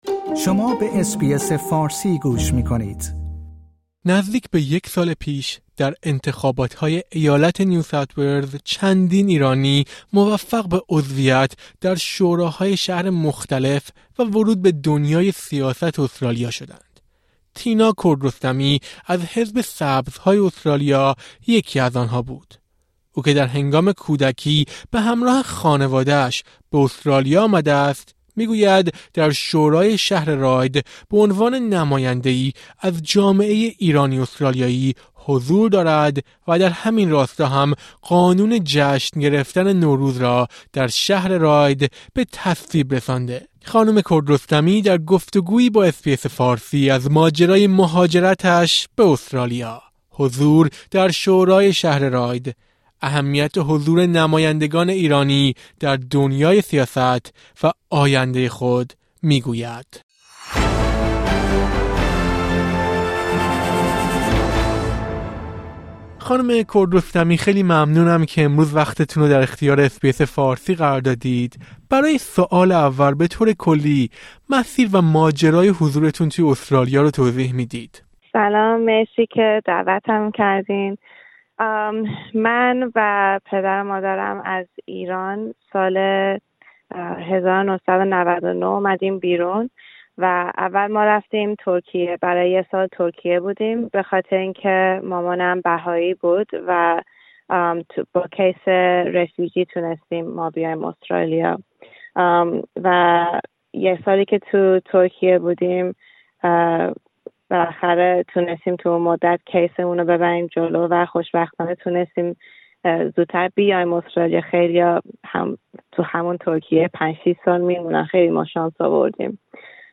تینا کردرستمی، عضو شورای شهر راید در نیوساوت‌ولز، در گفت‌وگویی با اس‌بی‌اس فارسی از ماجرای مهاجرت خود به استرالیا، فعالیت در خیزش «زن‌، زندگی، آزادی» و اهمیت حضور جامعه ایرانی‌ در دنیای سیاست استرالیا می‌گوید.